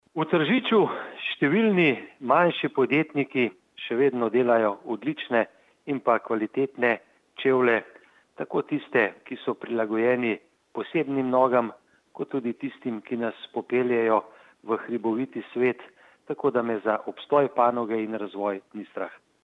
izjava_mag.borutsajoviczupanobcinetrzic_sustarska2.mp3 (473kB)